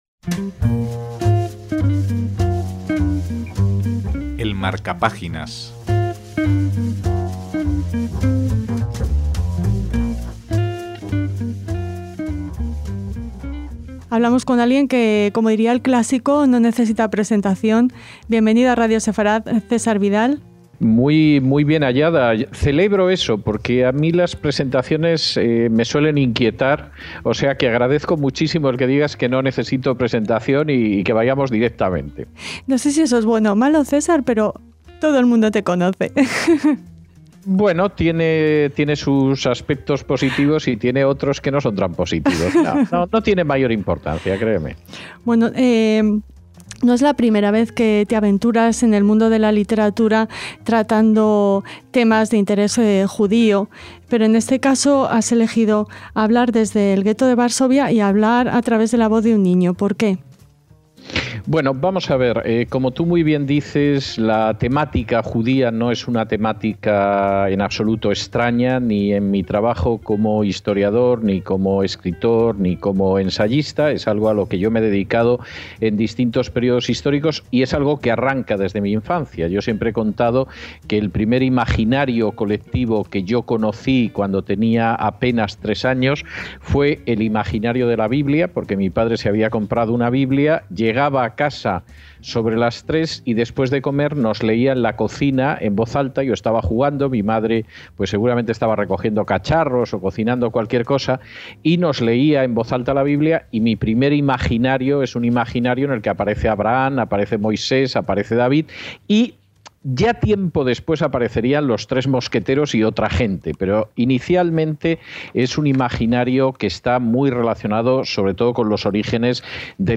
EL MARCAPÁGINAS - Un niño, Jacob, confinado en el gheto de Varsovia, es el protagonista de la nueva novela de César Vidal quién nos habla de este libro homenaje a personajes como el director del orfanato del gheto, el pedagogo, escritor y Justo entre las Naciones, Janusz Korczak.